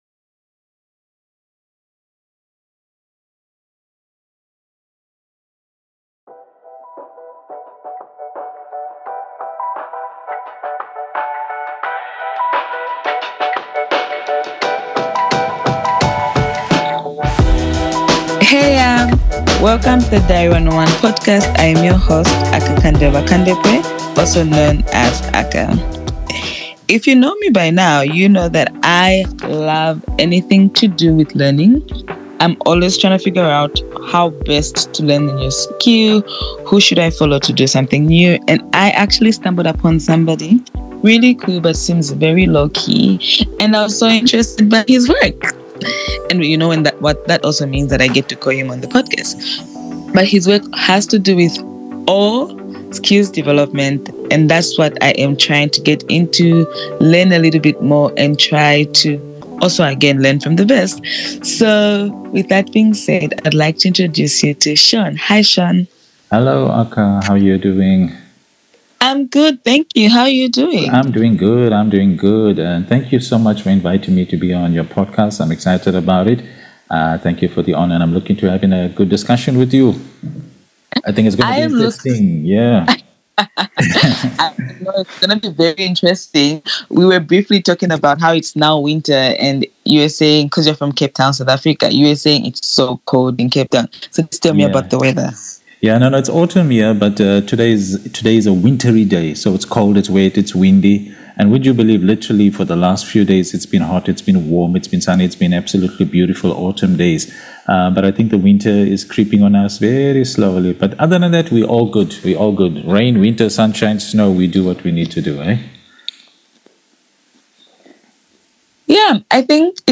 Plus, we share a few laughs over visa and travel stories. A heartfelt and curious conversation you won’t want to miss.🎧 Catch the full episode below.